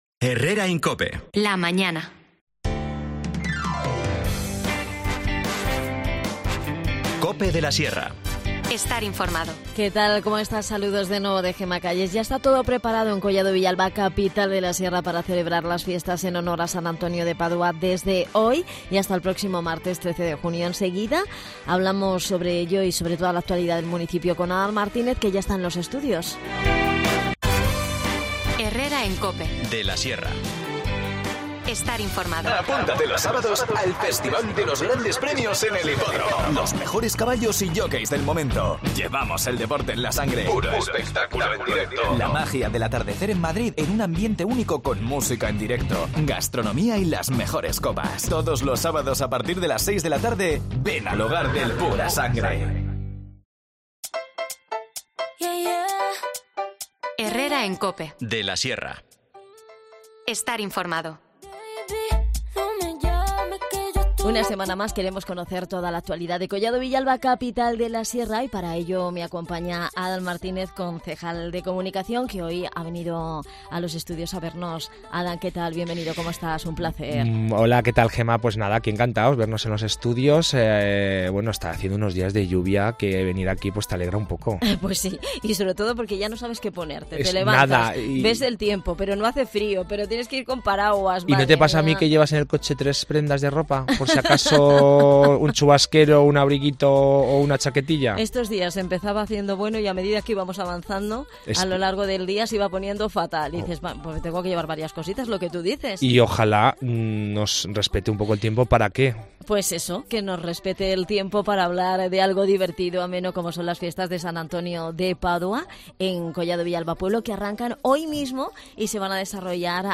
Adan Martínez, concejal de Comunicación en Collado Villalba, nos visita para hablarnos de las Fiestas de San Antonio de Padua en Collado Villalba-Pueblo que se desarrollarán del 8 al 13 de junio.